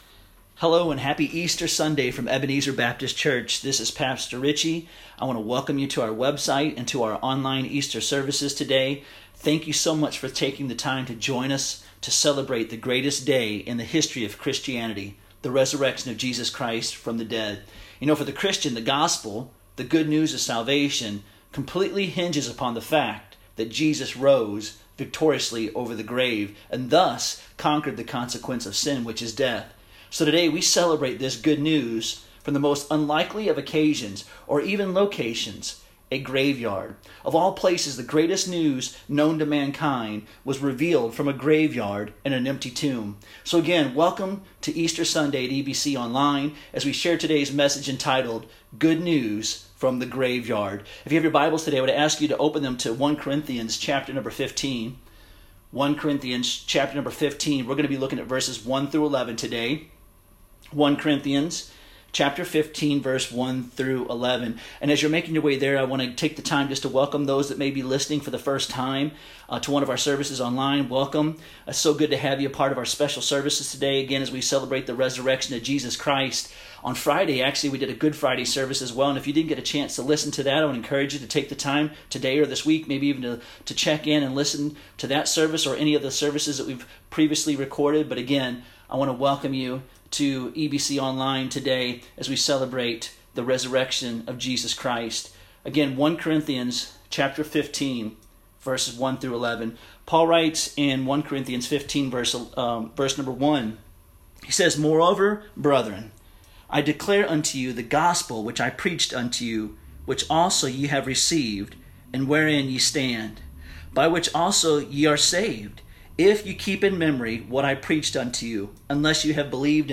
Easter Sunday Sermon